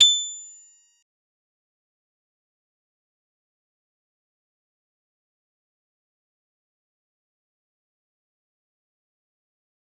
G_Musicbox-A8-mf.wav